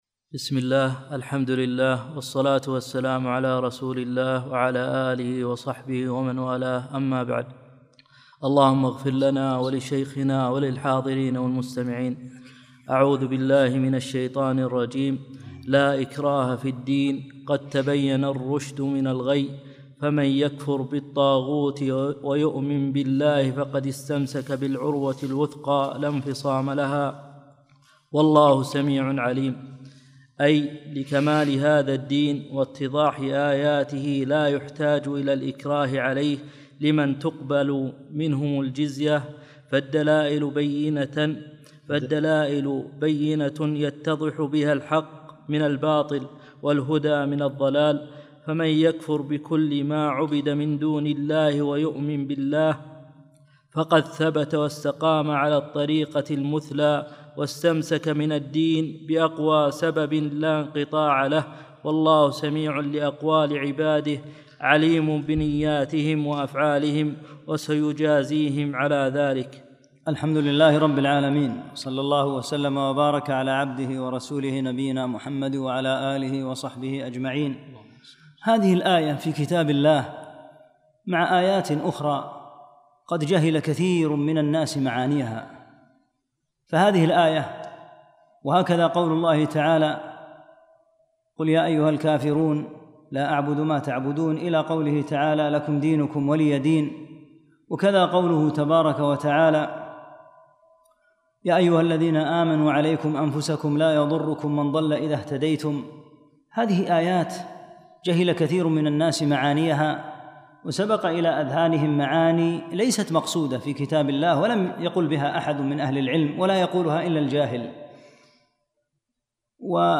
الدروس التفسير الميسر تفسير سورة البقرة